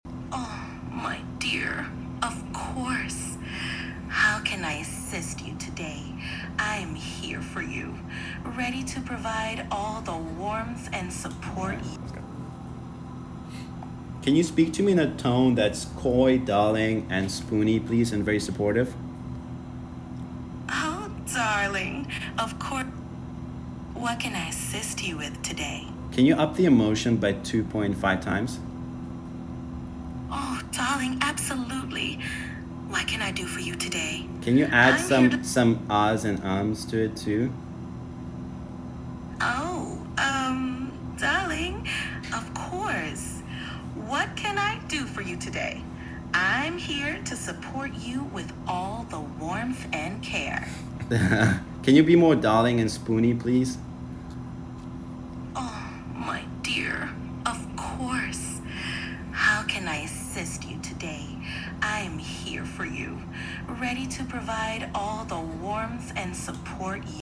ChatGPT hack sexy voice sound effects free download